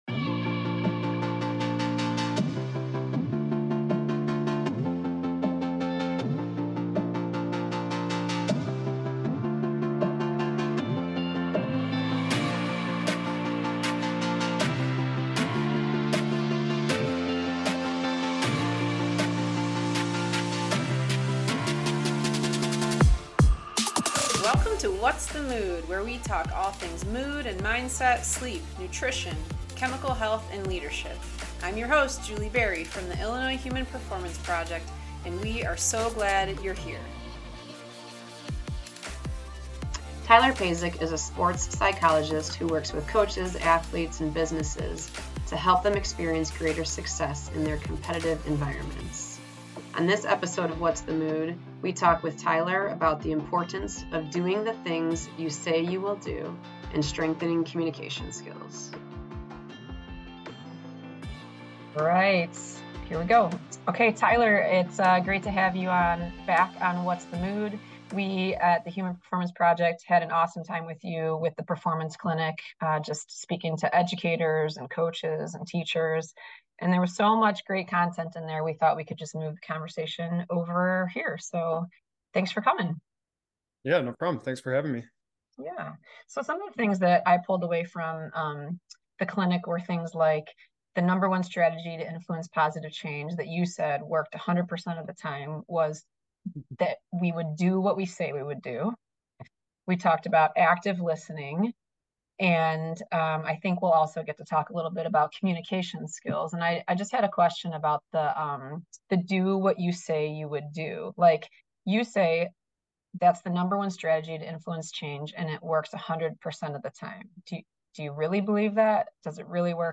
Our conversation